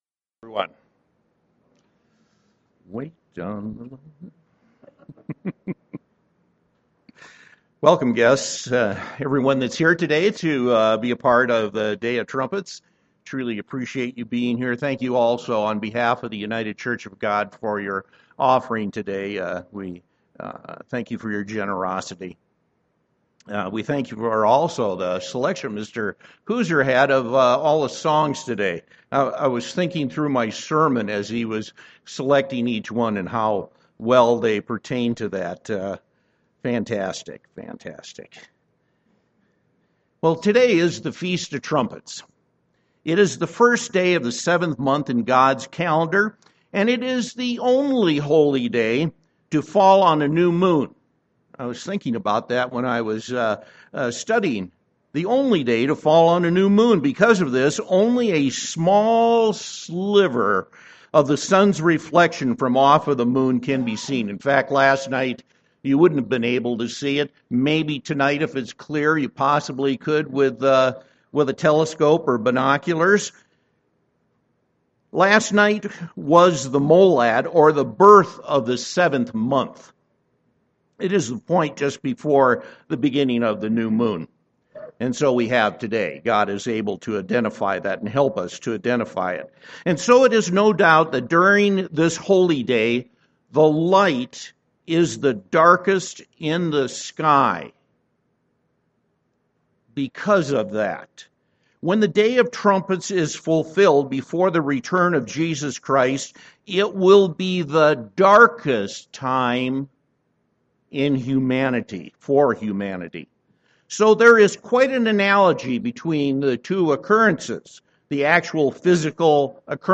UCG Sermon Studying the bible?
Given in Denver, CO